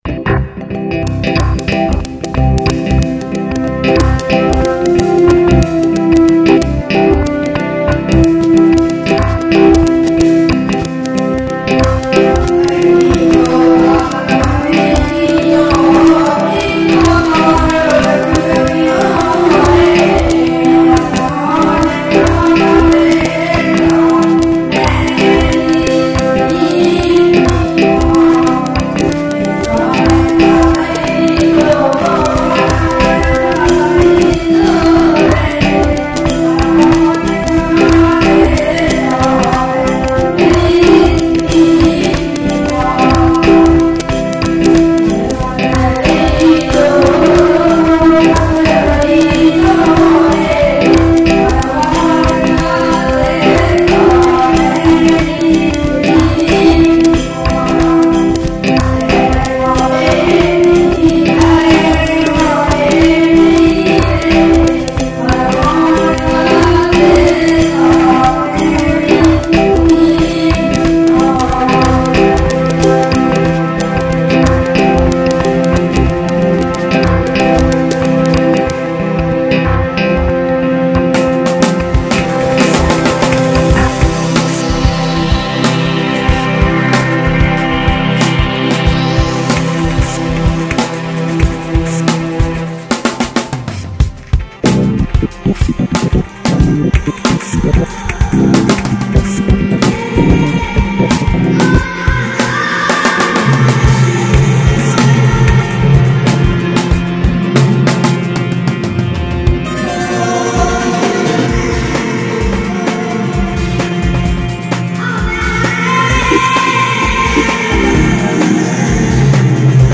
contemporary indian music